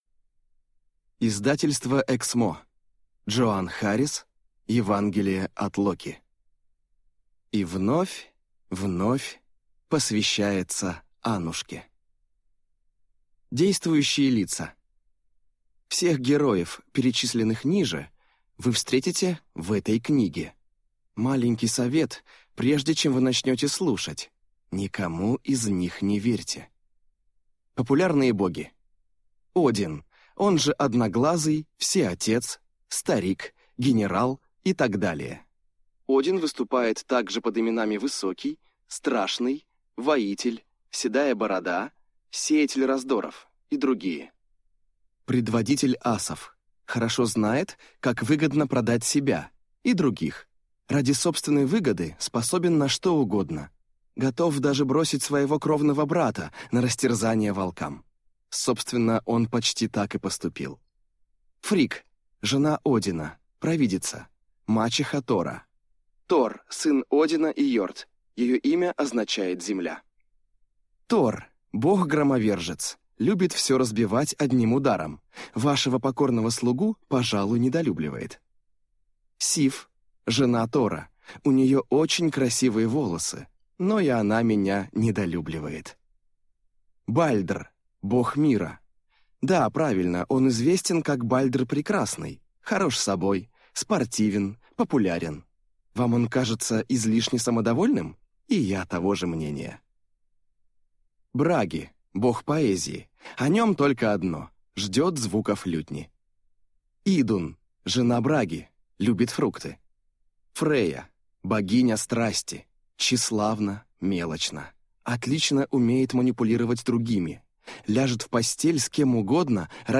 Аудиокнига Евангелие от Локи | Библиотека аудиокниг